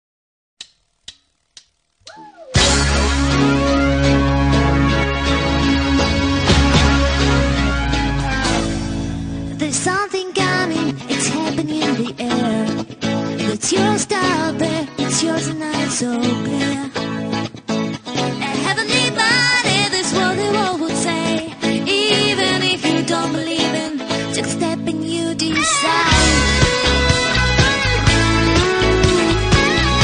Тут только припев!